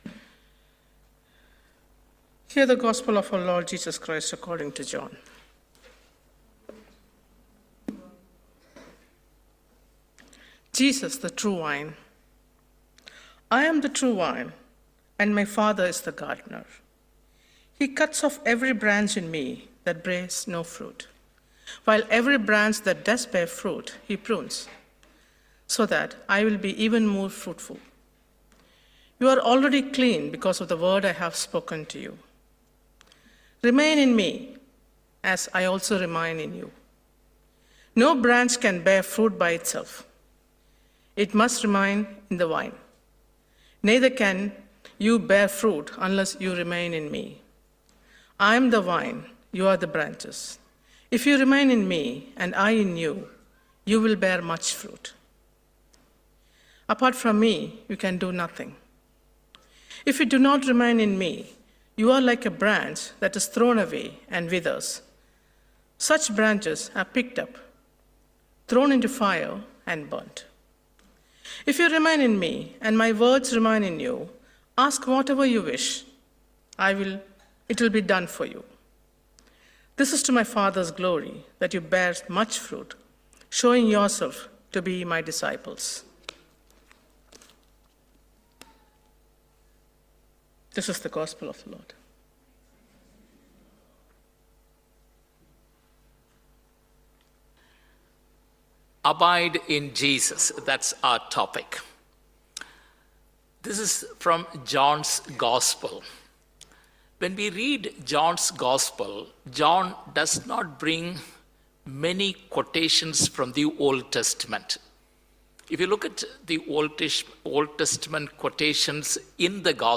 Sermon-April 28, 2024